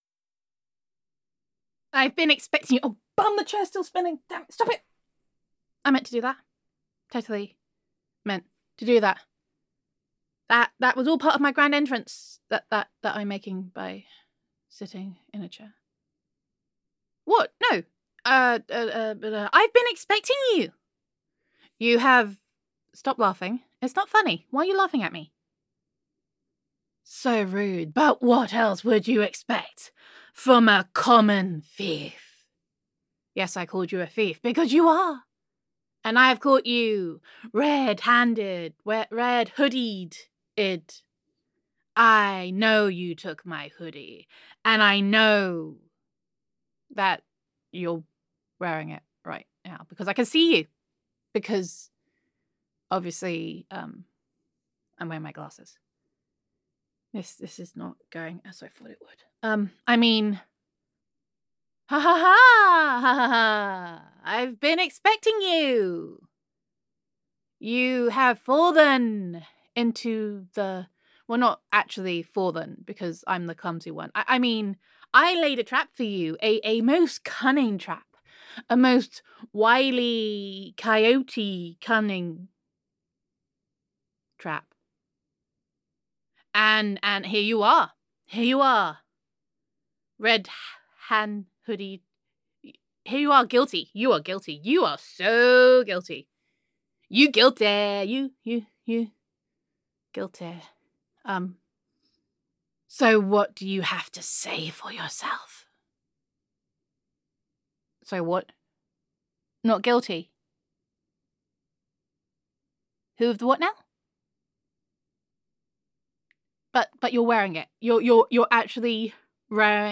[F4A] Caught Red Hoodie Handed [A Cunning Trap][Catching a Low Down Hoodie Thief][Reverse Uno Card][Ridiculous][Caught Red Hoodie Handed][Gender Neutral][Your Girlfriend Catches You With a Illicit Contraband Hoodie, Only Not So Much]